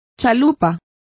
Complete with pronunciation of the translation of sloop.